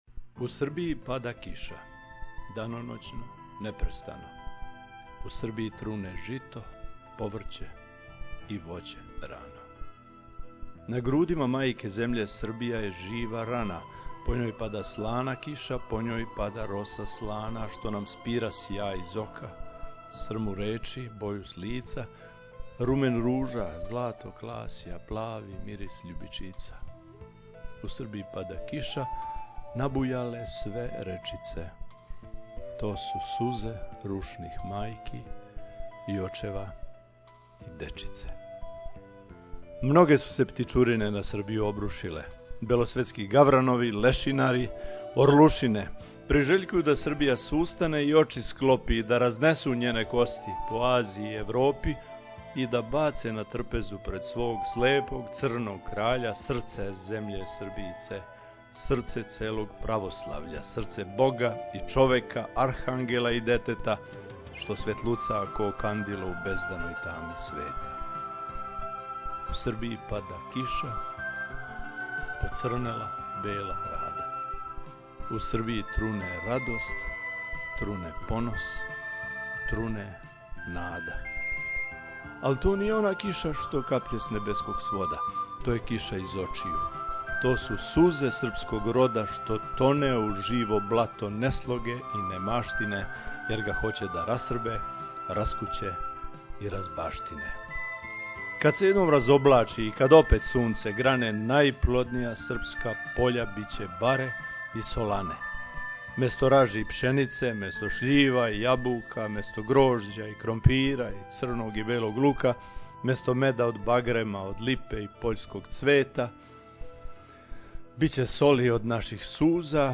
KisaUSrbijiSaMuzikom.mp3